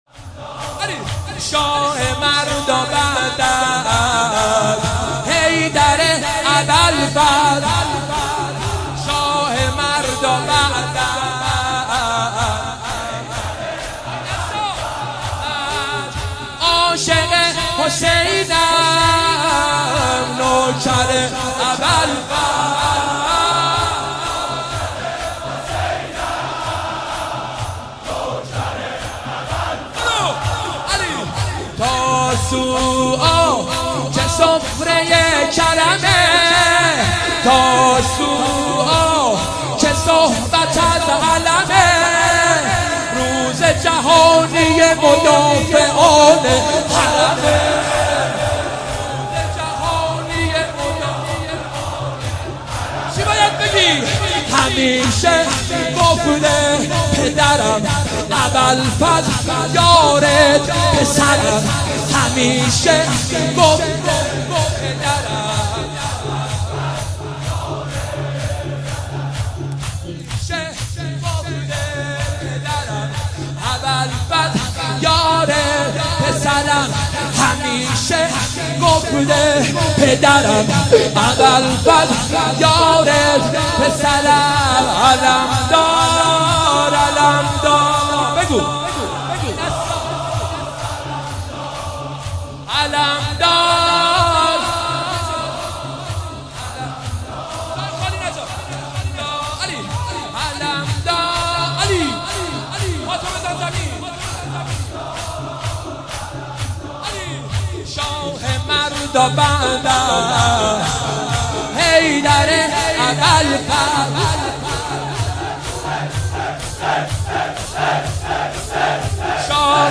مداحی اربعین
نوحه جديد